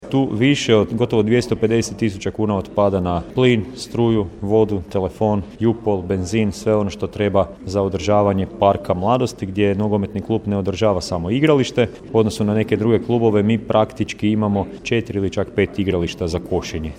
NK Mladost Komet, redovna godišnja skupština kluba, 10.2.2023. / Poduzetnički centar Prelog